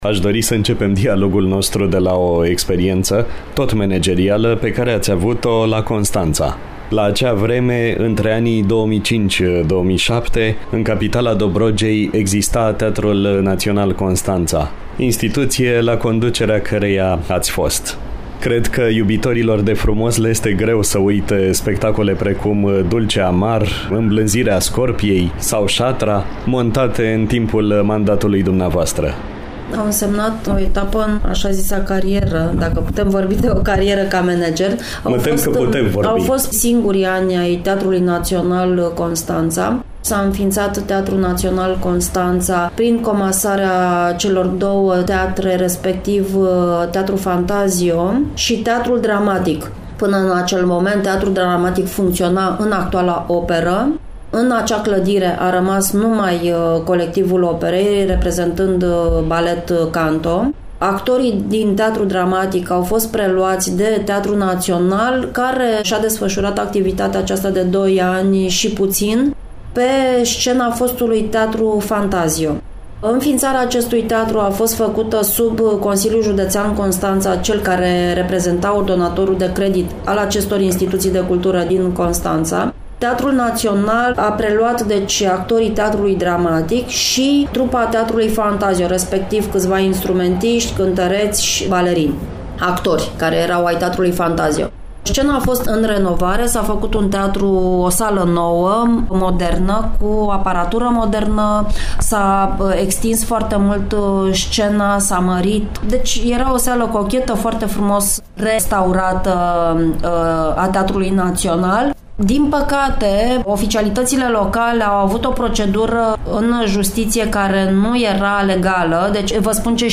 (INTERVIU)
Un interviu